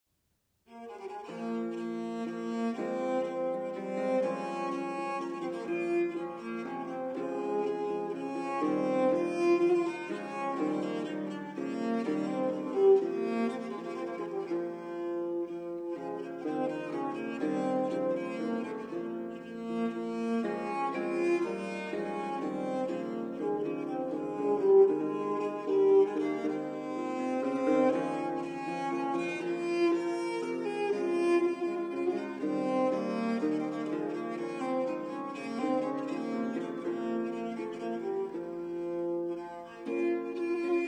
ALLA FRANCESCA